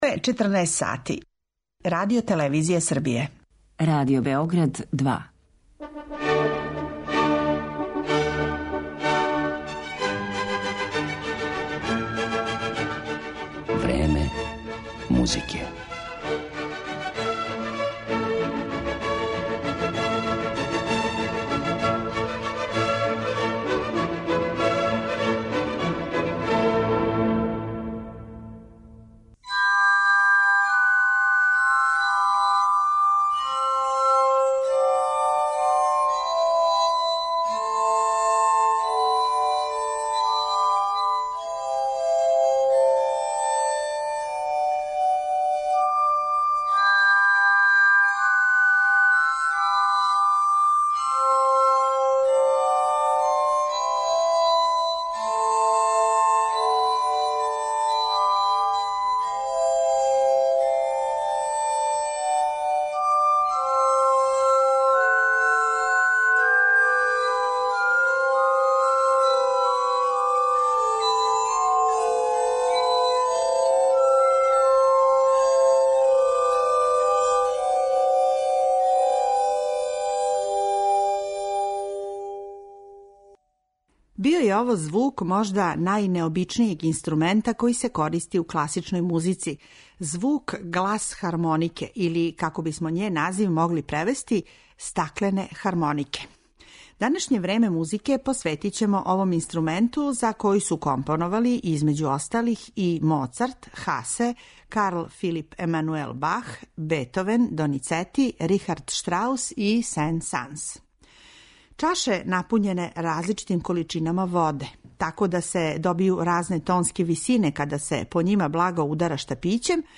Звук гласхармонике